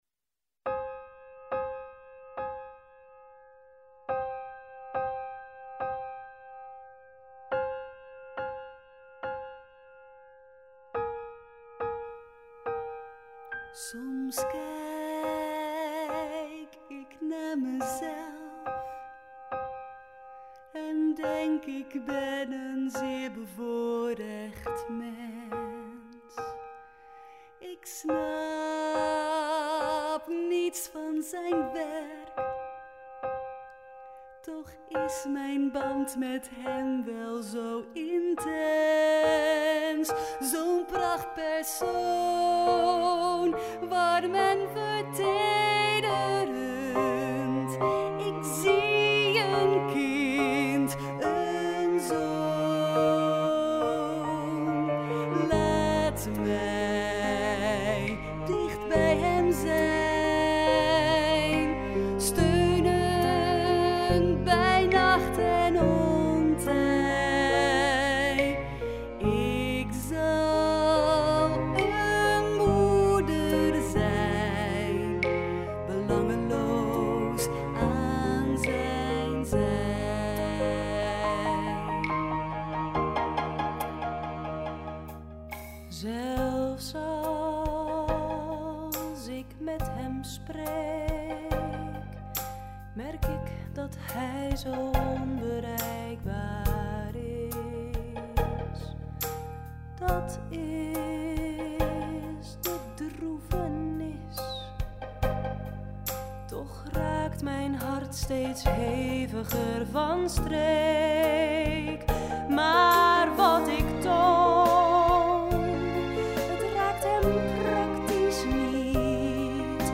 Uit “Da Vinci” (musical)